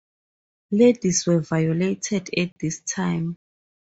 Pronounced as (IPA) /ˈvaɪəˌleɪtɪd/